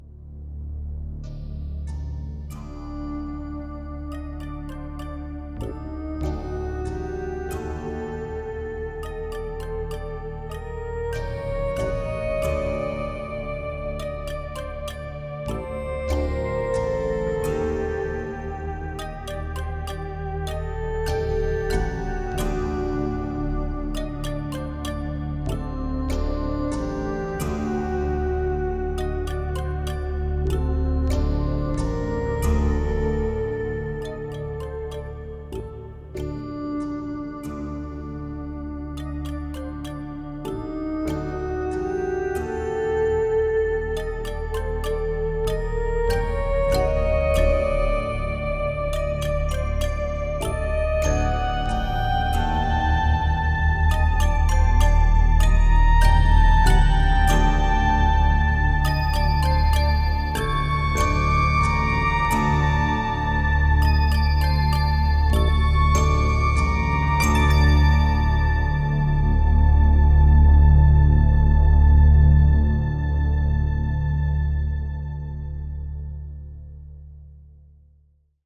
heel ontspannend, met nogal wat natuurgeluiden